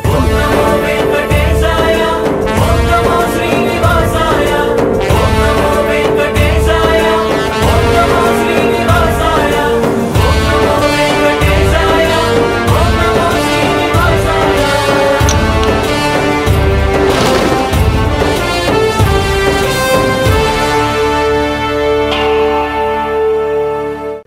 CategoryDevotional Ringtones, Telugu Ringtones